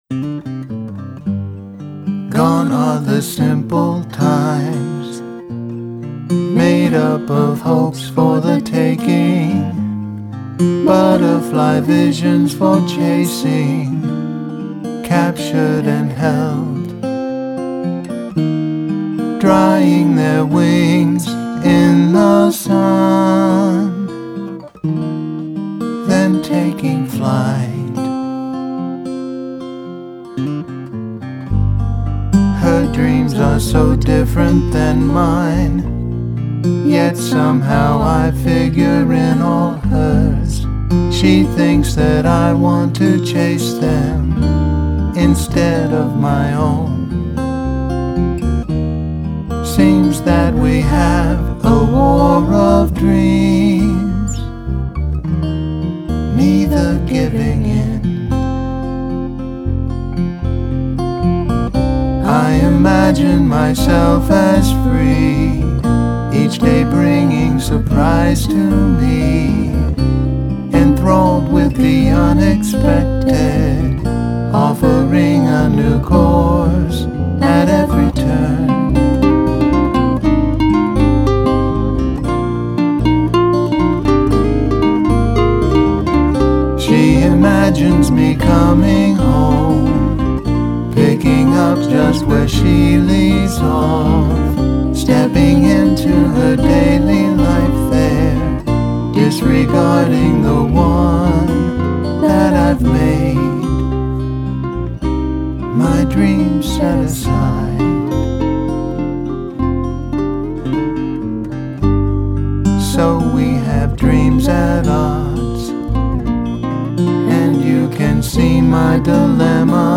Music
guitar and vocals